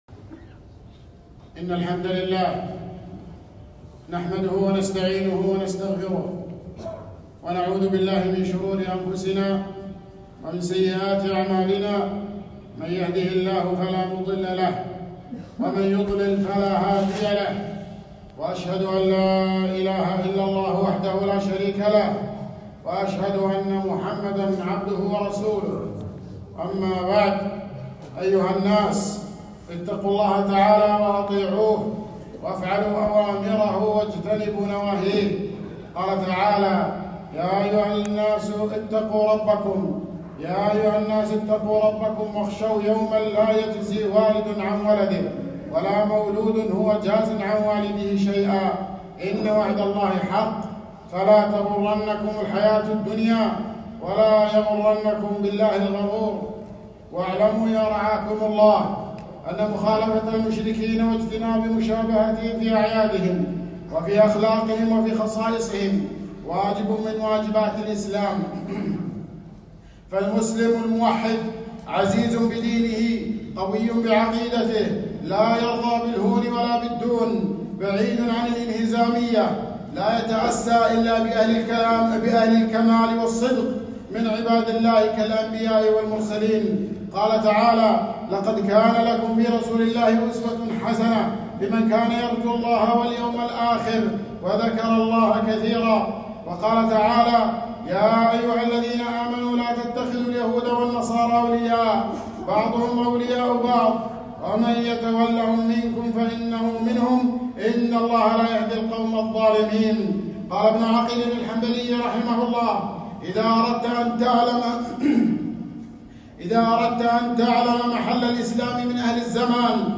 خطبة - عيد الحب